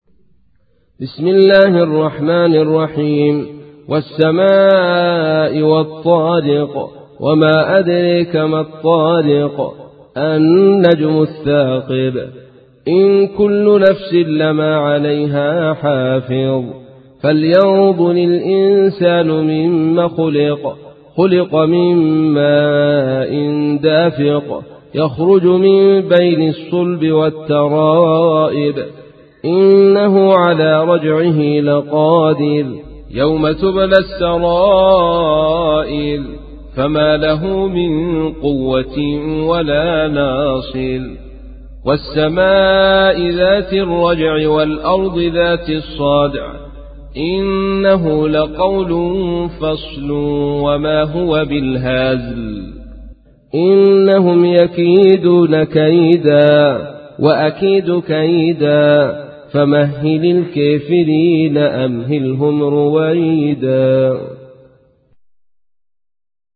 تحميل : 86. سورة الطارق / القارئ عبد الرشيد صوفي / القرآن الكريم / موقع يا حسين